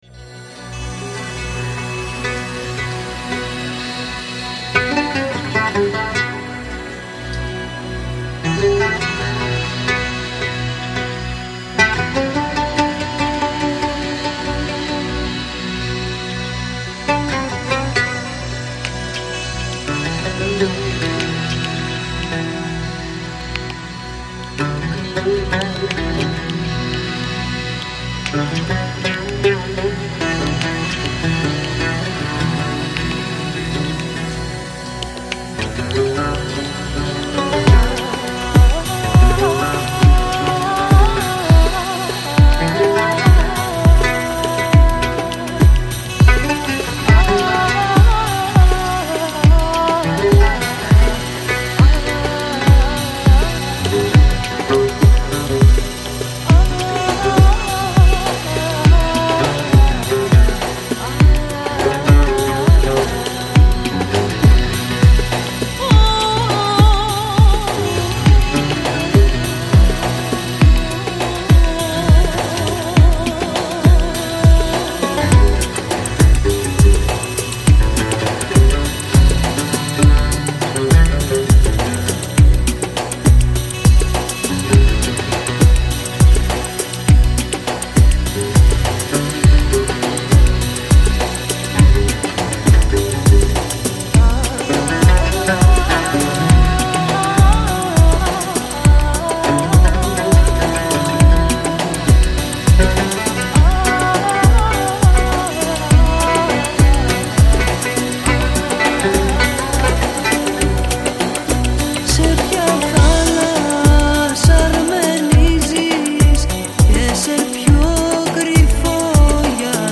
Жанр: Chill Out, Downtempo, Organic House, Ethnic, World